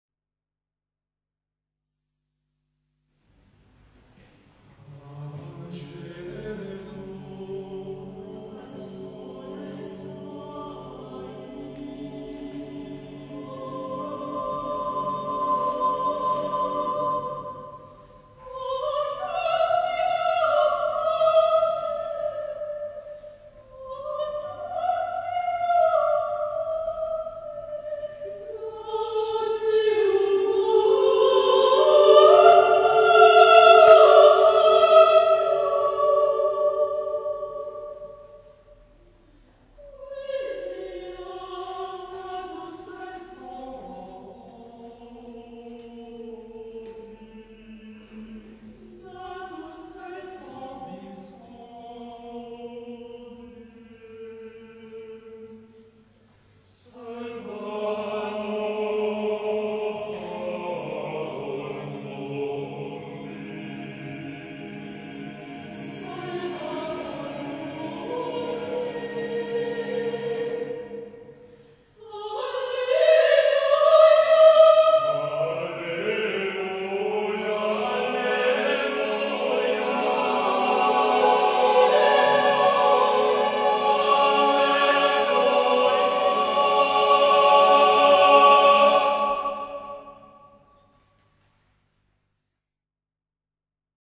· Contenuto in Choir-Sacred a Cappella
live